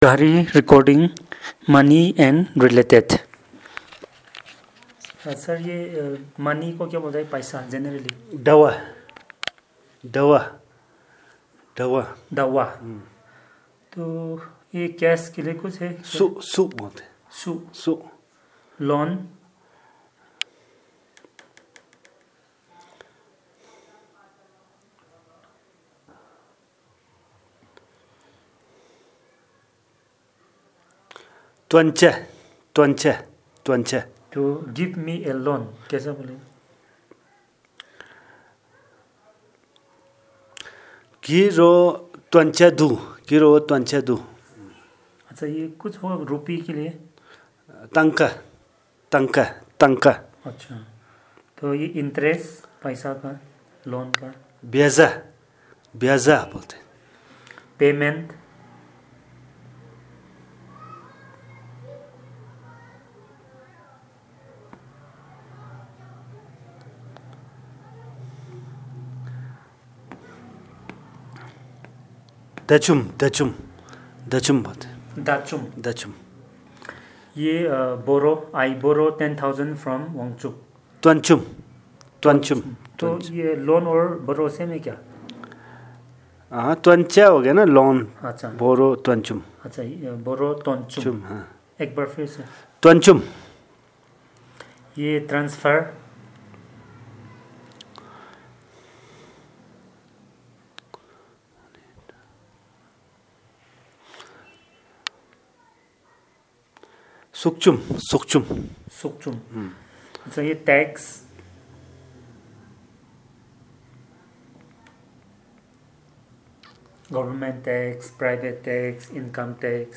Elicitation of words about money and related items